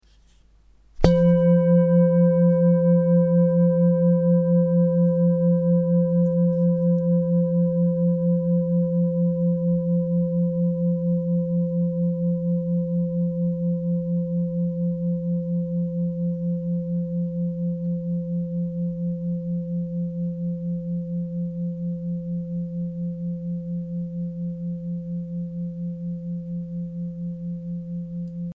Polierte Tibetische Klangschale - CHIRON
Durchmesser: 25,5 cm
Grundton: 172,36 Hz
1. Oberton: 516,85 Hz